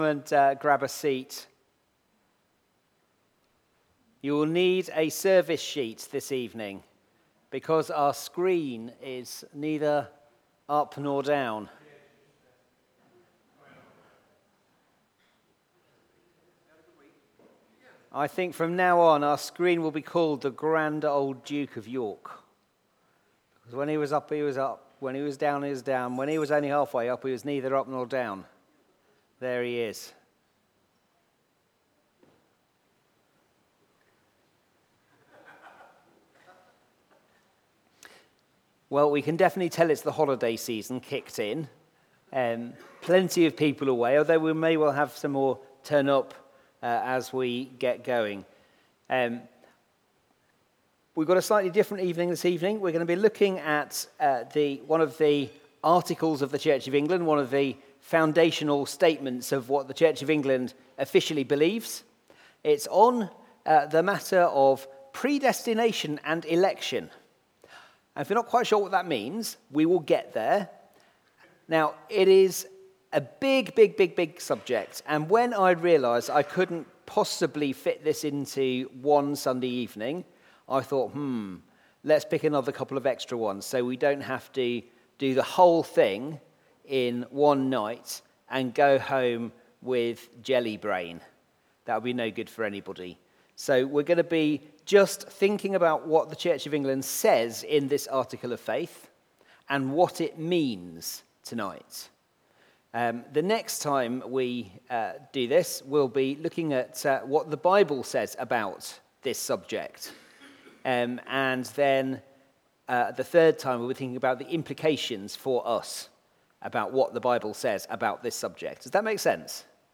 Media Library We record sermons from our Morning Prayer, Holy Communion and Evening services, which are available to stream or download below.
The Articles Theme: Article XVII: Predestination and Election Sermon Search